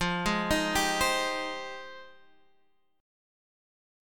F Minor 9th